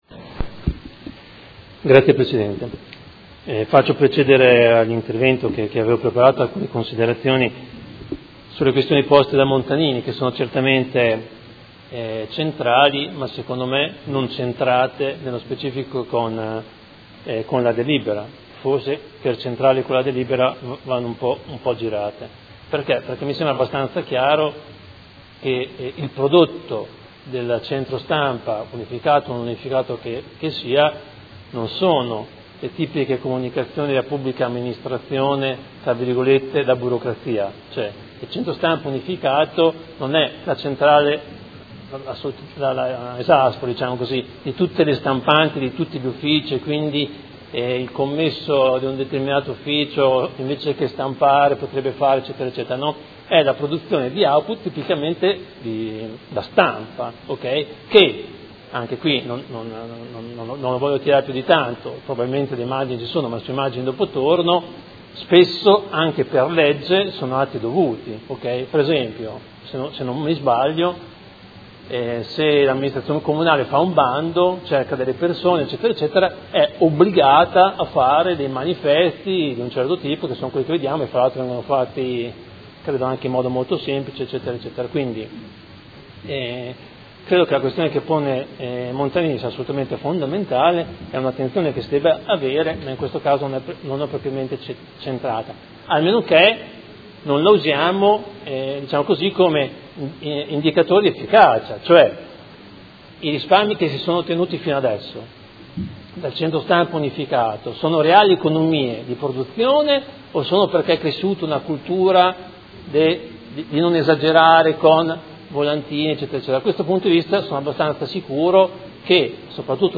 Seduta del 16/06/2016. Proposta di deliberazione: Schema di Convenzione tra il Comune di Modena, l’Università degli Studi di Modena e Reggio Emilia e l’Azienda Ospedaliero Universitaria di Modena per la realizzazione di un Centro Stampa unificato - Approvazione.